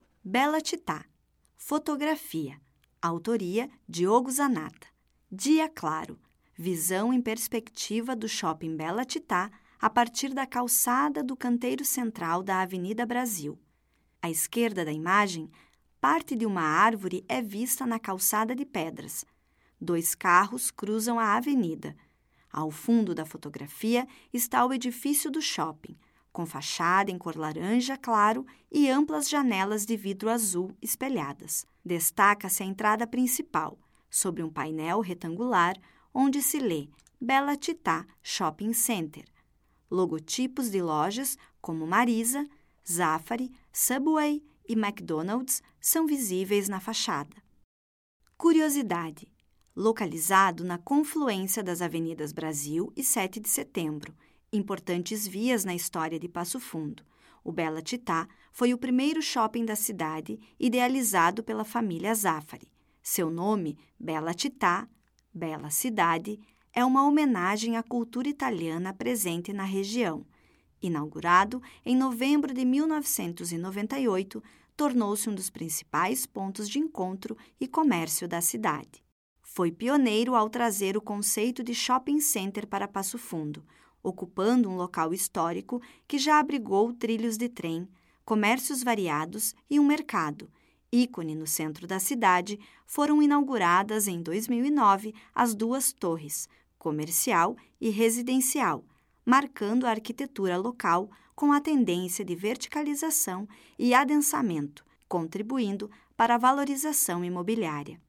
Audioguia
Audiodescrição